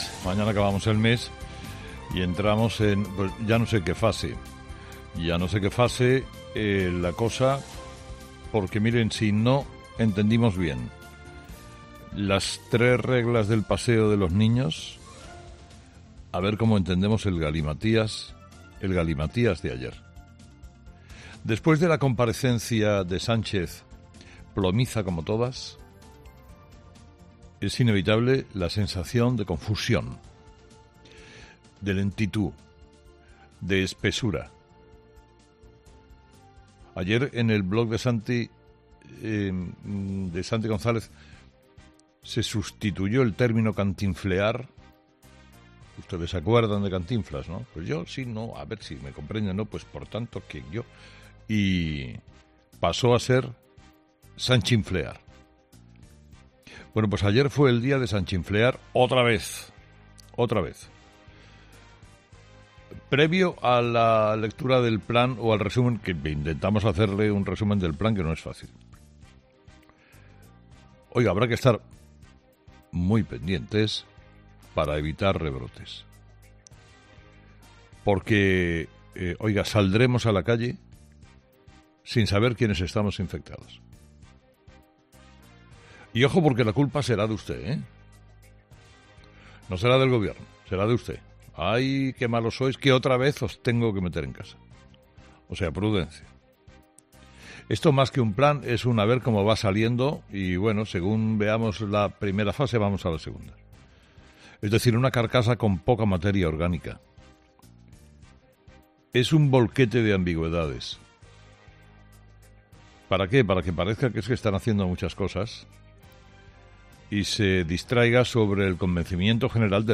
En su monólogo de las seis de la mañana, Carlos Herrera se ha referido a la comparecencia de este martes del presidente del Gobierno, Pedro Sánchez , en la cual anunció el plan del Ejecutivo para la desescalada del confinamiento.